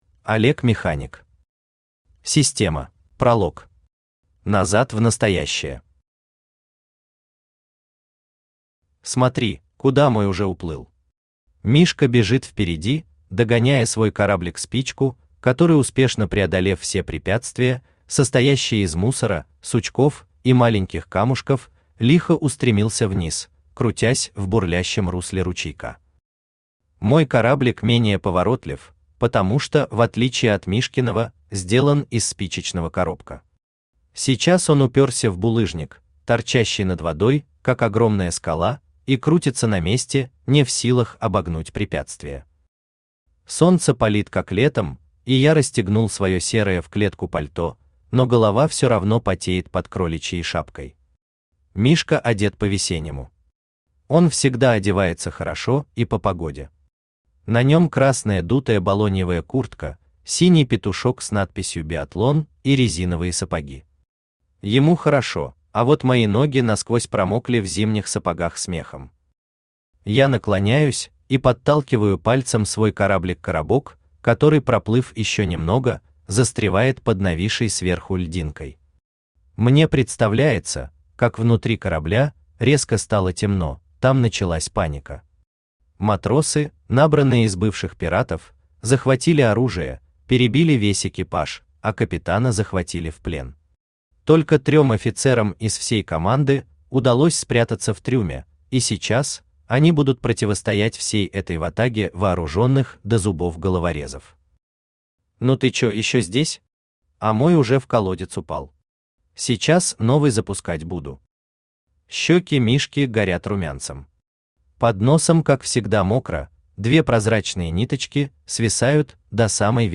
Аудиокнига Система | Библиотека аудиокниг
Aудиокнига Система Автор Олег Механик Читает аудиокнигу Авточтец ЛитРес.